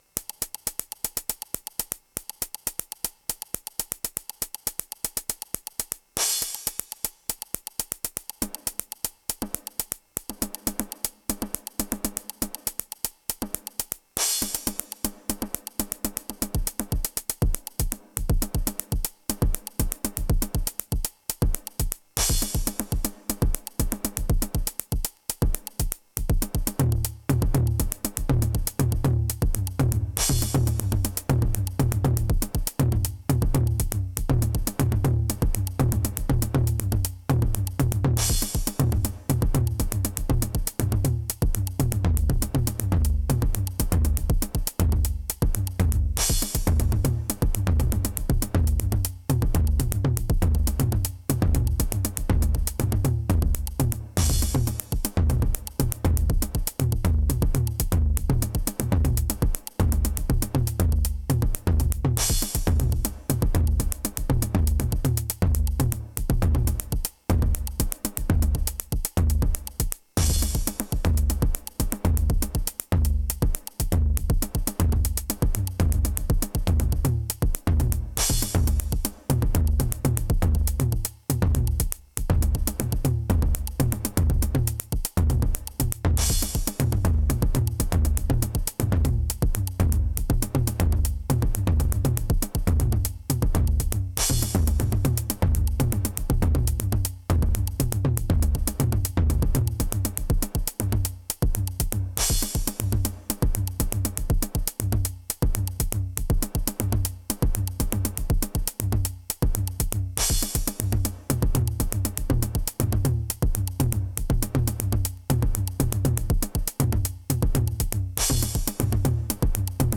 Polyrythmic Techno System groove inspired by the Toms discussion: Advice on Drum programming Toms?